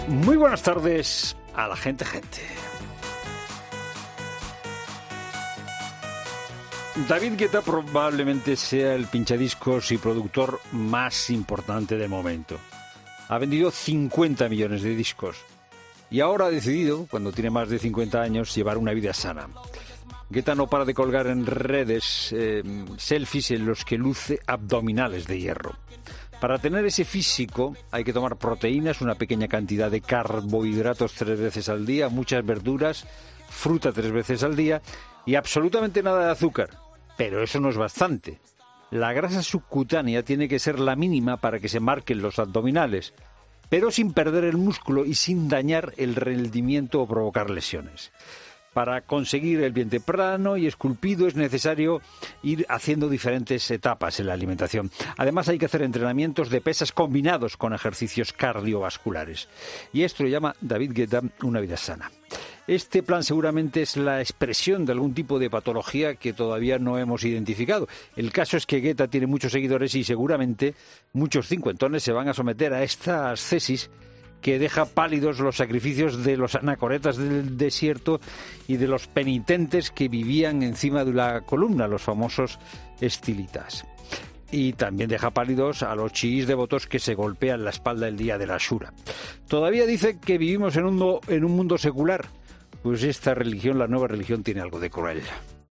El copresentador de La Tarde reflexionó sobre la rutina física que sigue el DJ, con el que casi comparte edad